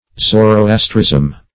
Zoroastrism \Zo`ro*as"trism\, n.